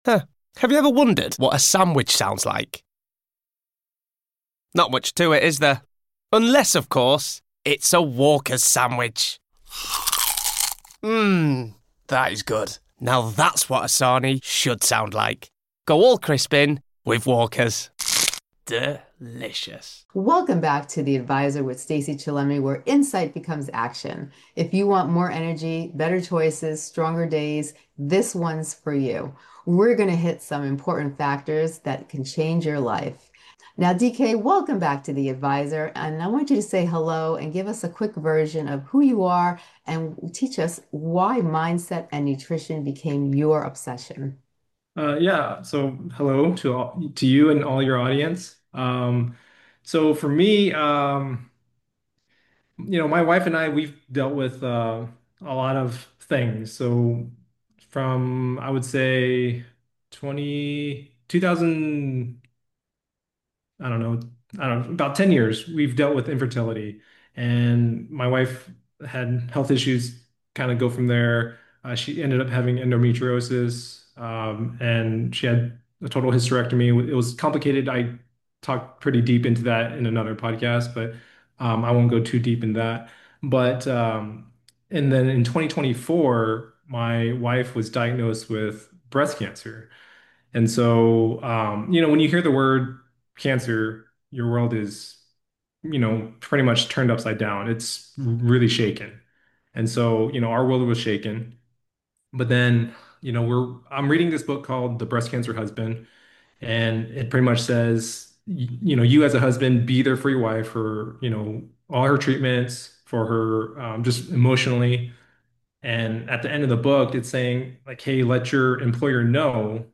This conversation hands you the playbook—mind first, plate second.